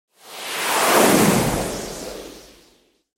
دانلود آهنگ باد 35 از افکت صوتی طبیعت و محیط
جلوه های صوتی
دانلود صدای باد 35 از ساعد نیوز با لینک مستقیم و کیفیت بالا